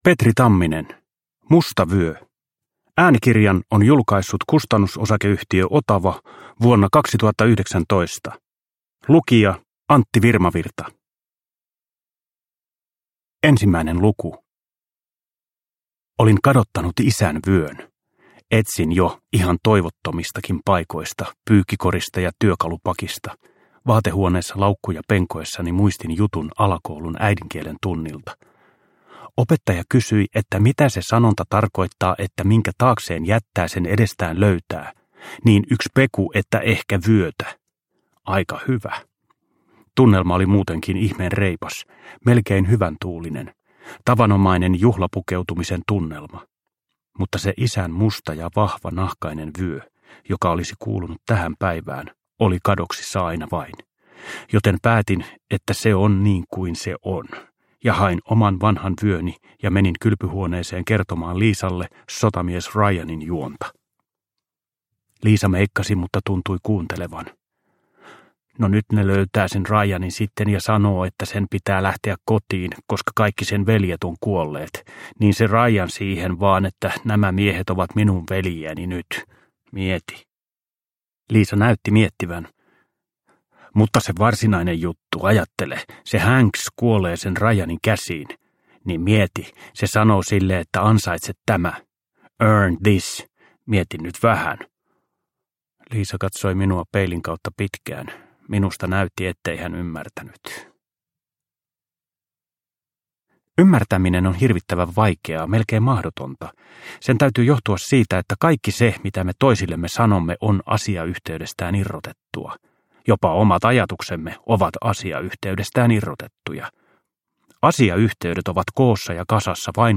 Musta vyö – Ljudbok – Laddas ner
Uppläsare: Antti Virmavirta